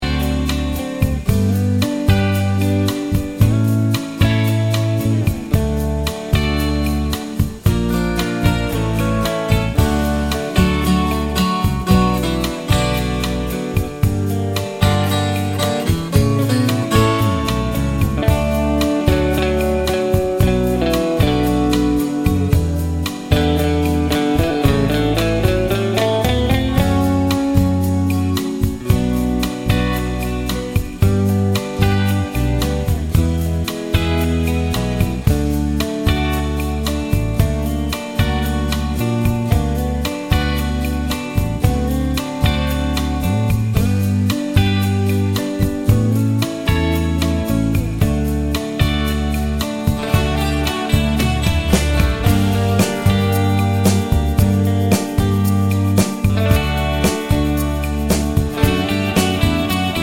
no Backing Vocals Soundtracks 2:52 Buy £1.50